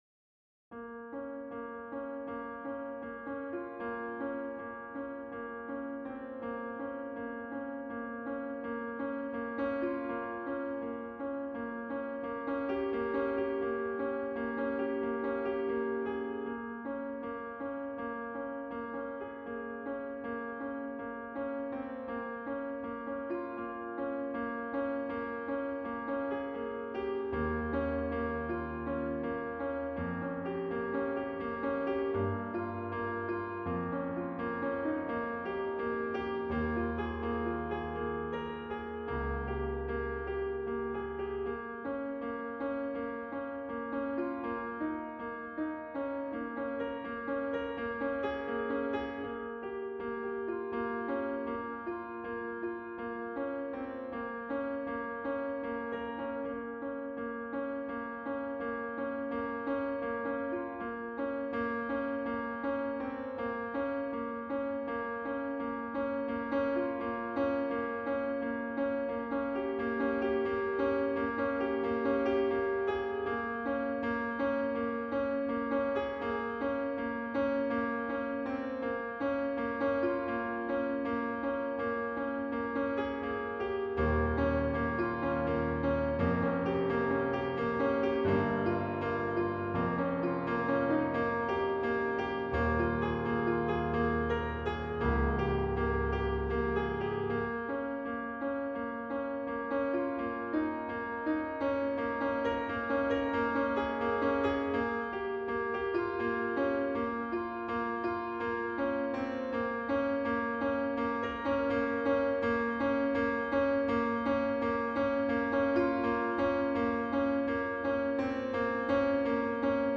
piano Duration